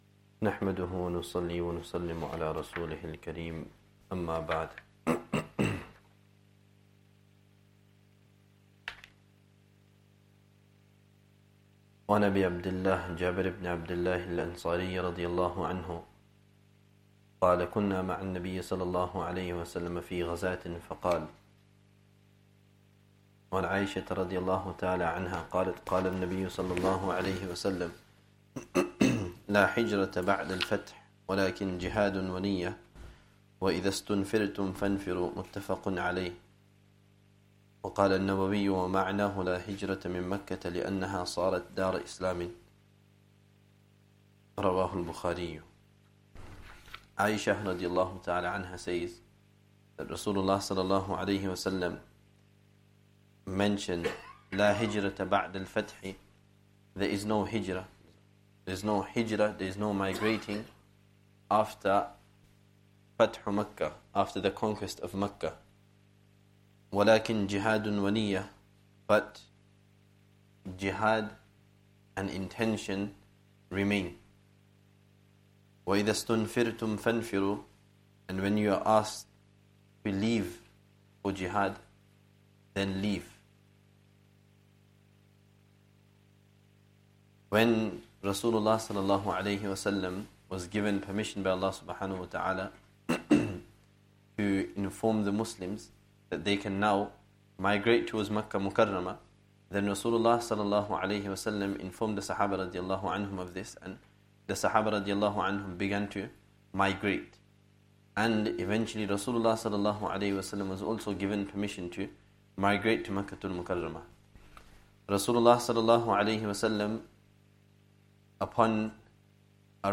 Lessons from Hadeeth